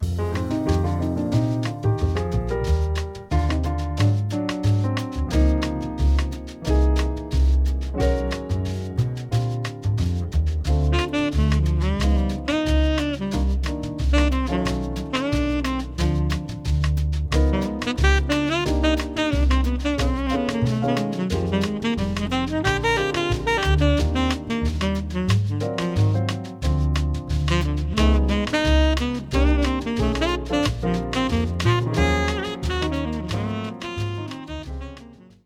Bossa nova
Key: Bb
BPM: 180
Time Signature: 4/4